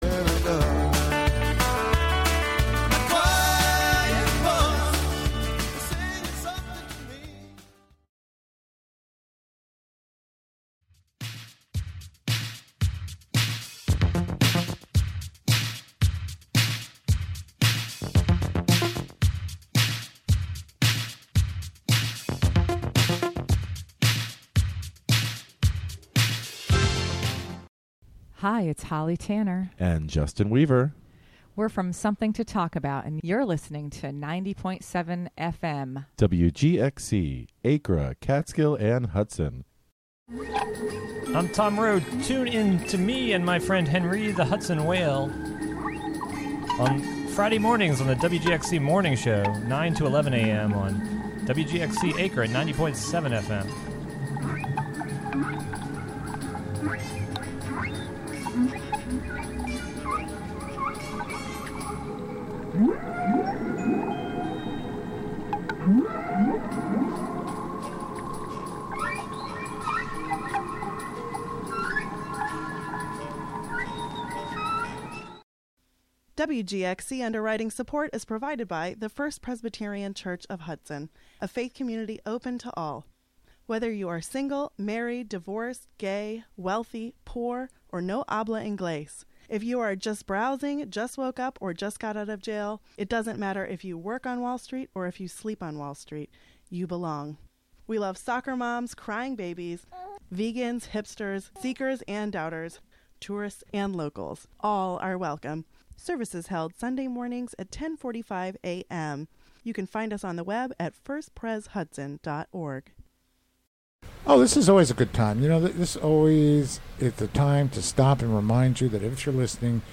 Hear Democrat Antonio Delgado speak this past Sat....
Hear Democrat Antonio Delgado speak this past Sat., Oct. 13 in Averill Park, on the campaign trail in the 19th Congressional District.